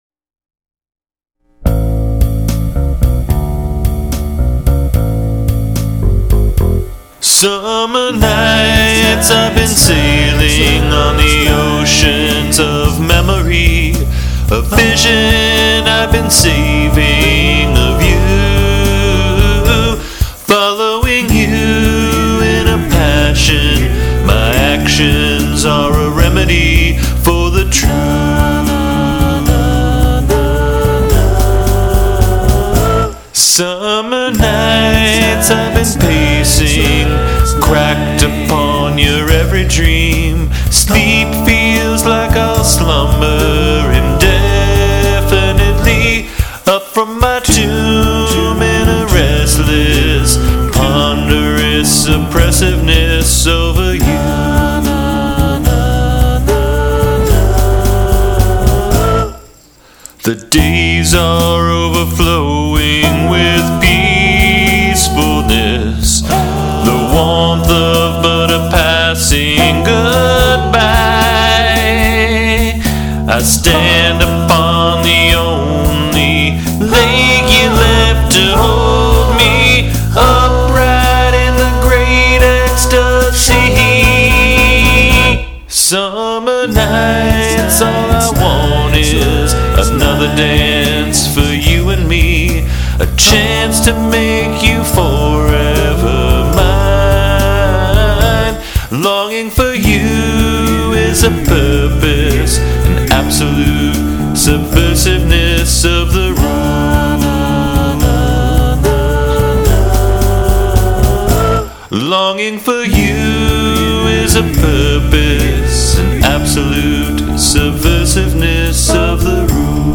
The chapter starts with a nostalgia-inflected description of “winsome days” and “seducing nights” that reminded me of love songs from the ’50’s and early ’60’s.